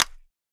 button_click.wav